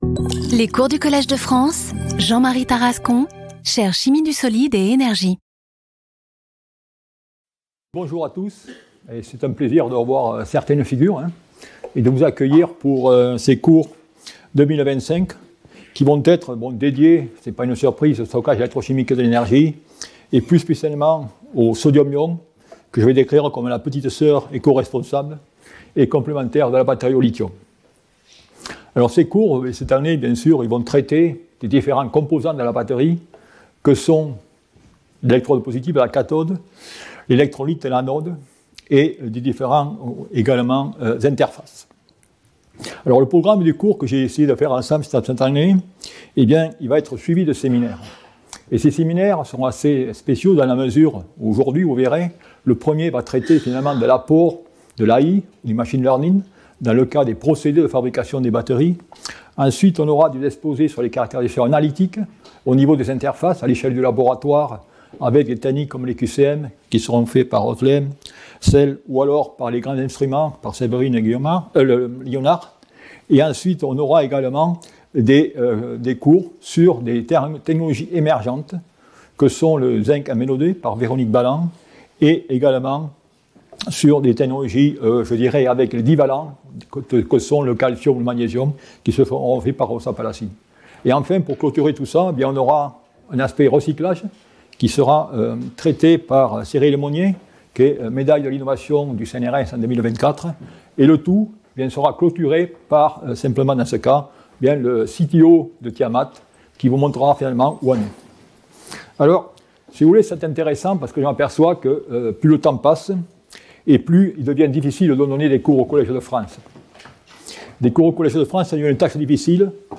Jean-Marie Tarascon Professor at the Collège de France
Lecture